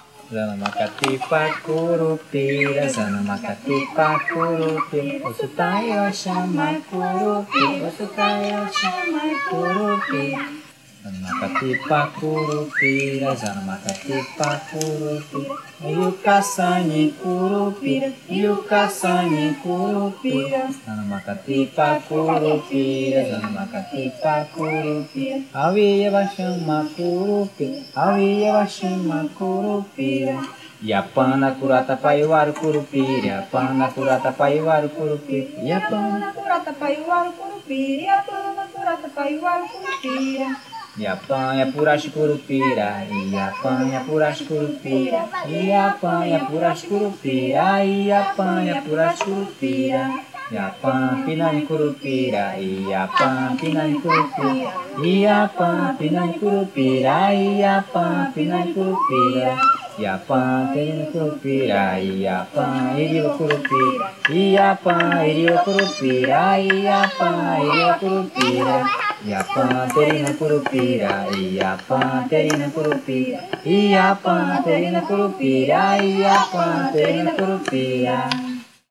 9.-Curupira-–-Kambeba-Omágua-Aldeia-Tururukari-uka_EDIT.wav